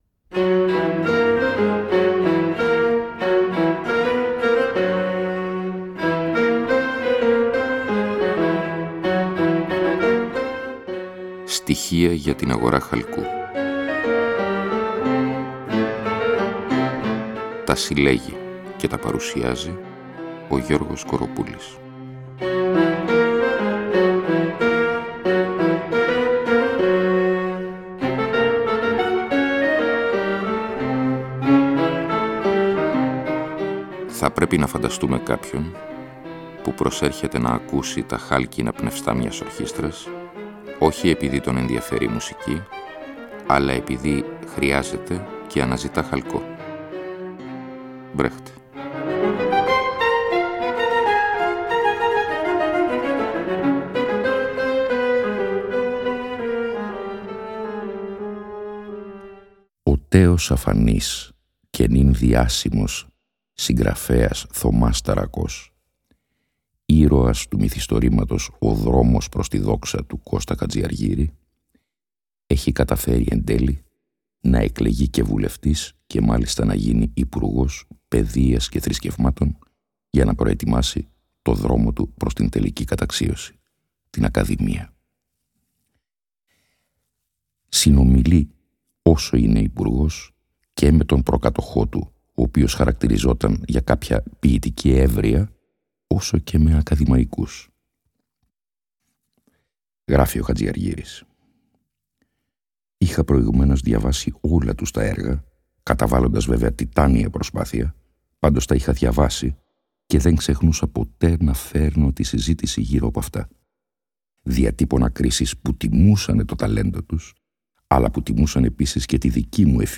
Εκπομπή λόγου. Ακούγονται, ερμηνεύονται και συγκρίνονται με απροσδόκητους τρόπους κείμενα λογοτεχνίας, φιλοσοφίας, δοκίμια κ.λπ. Η διαπλοκή του λόγου και της μουσικής αποτελεί καθ εαυτήν σχόλιο, είναι συνεπώς ουσιώδης.